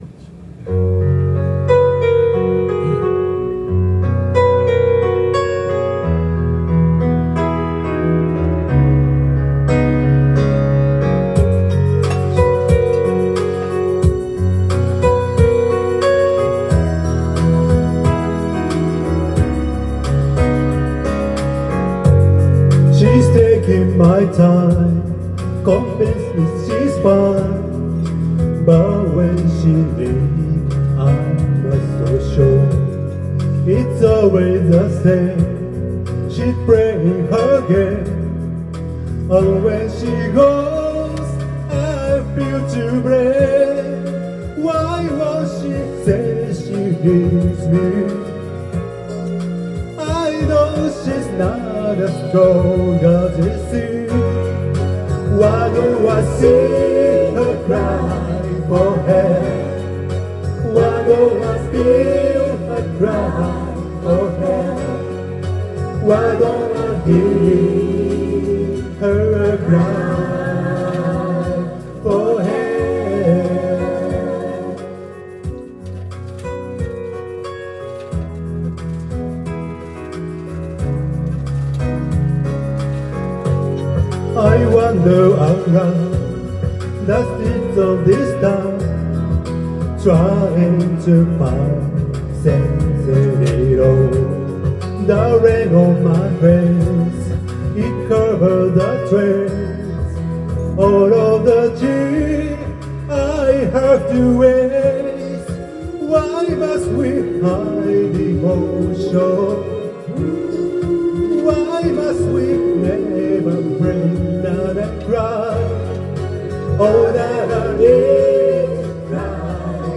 Duet & Chorus Night Vol. 22 TURN TABLE